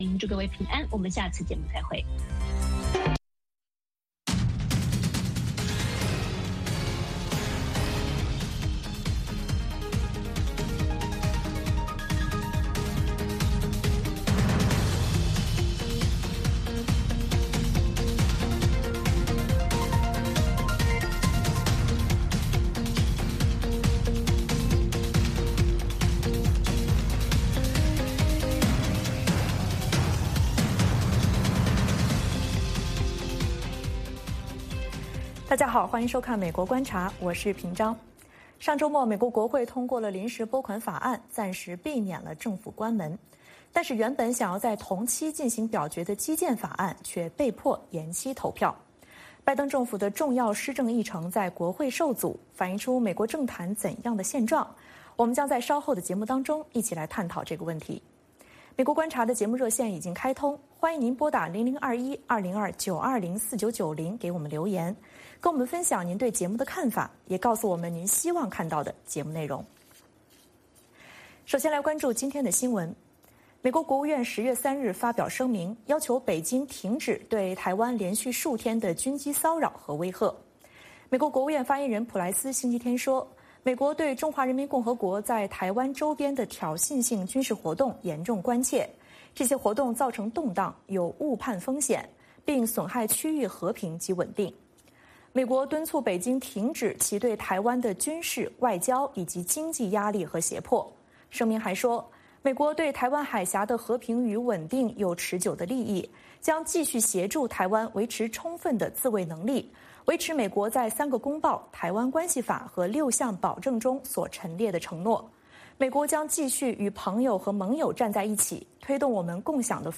北京时间早上6点广播节目，电视、广播同步播出VOA卫视美国观察。
节目邀请重量级嘉宾参与讨论。